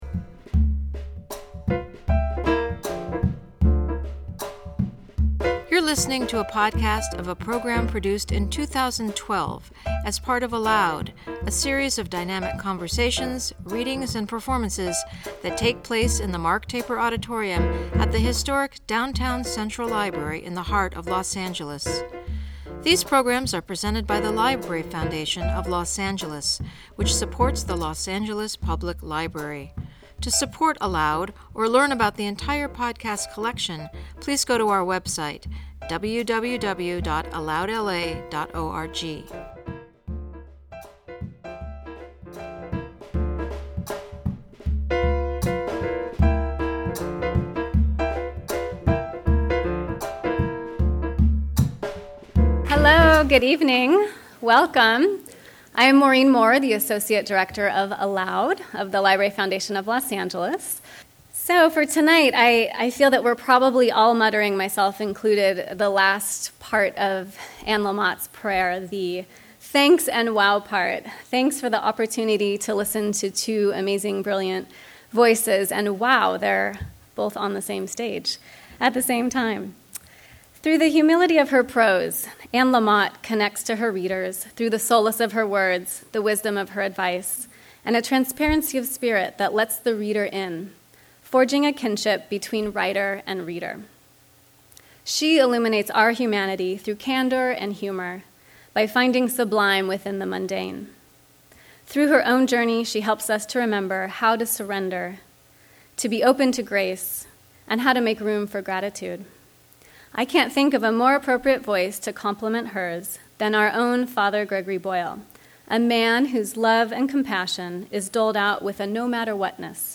In conversation with Father Gregory Boyle